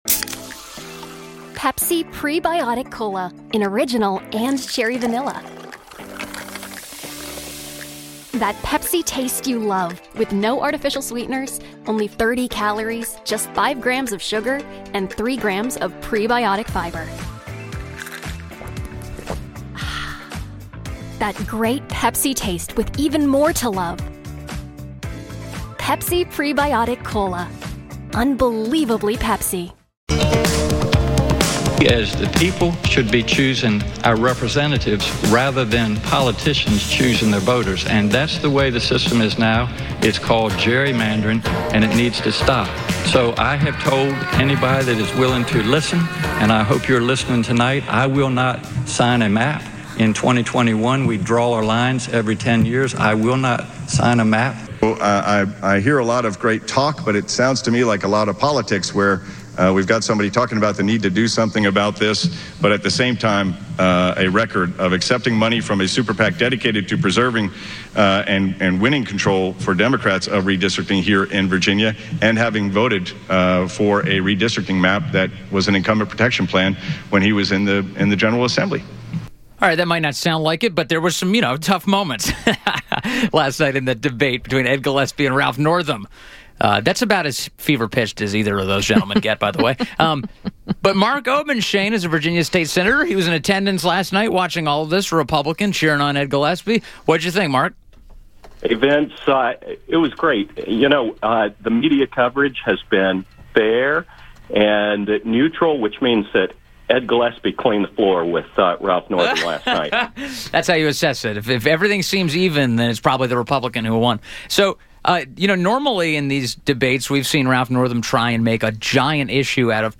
INTERVIEW – MARK OBENSHAIN – member of the Senate of Virginia from Harrisonburg